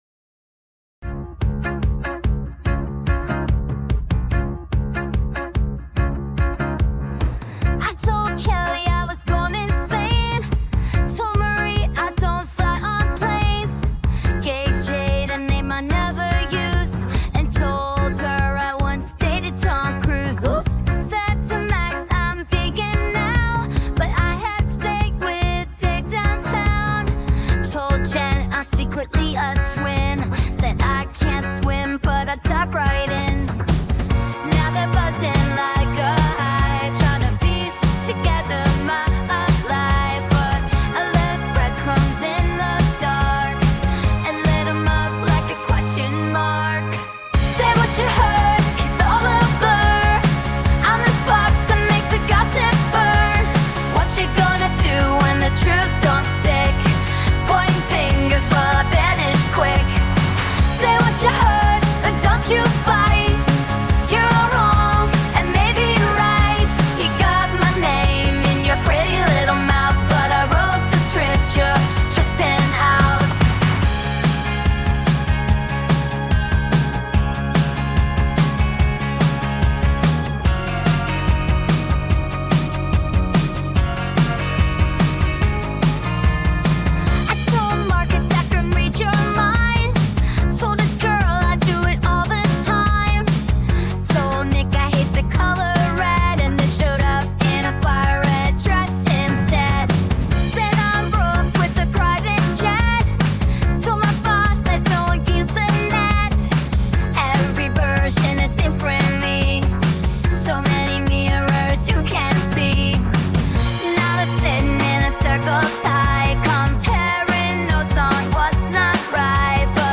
• Genre: Indie Pop/Electro